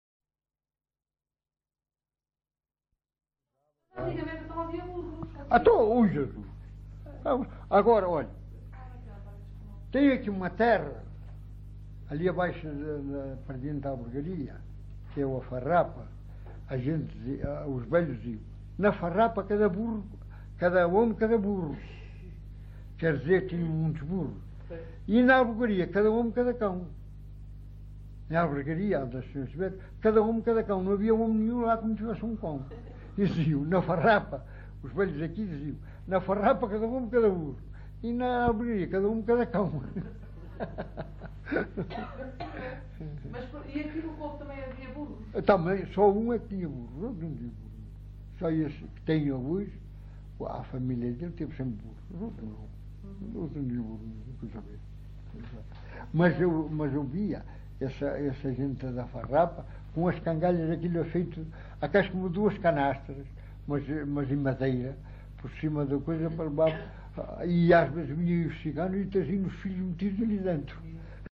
LocalidadeCovo (Vale de Cambra, Aveiro)